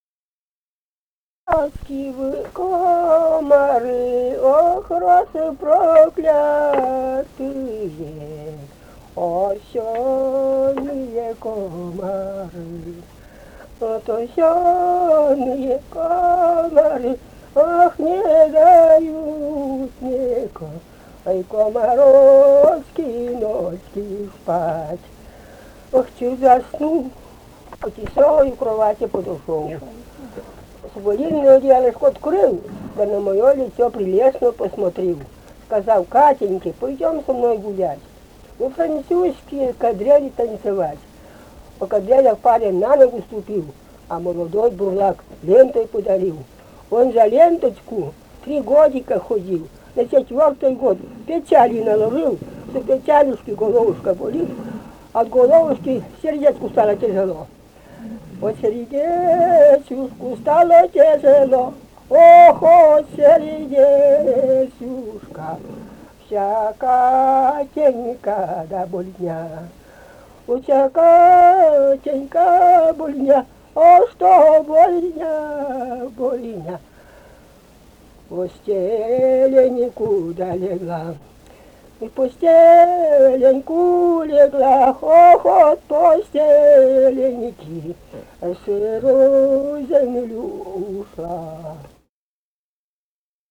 «Комарочки, вы комары» (лирическая).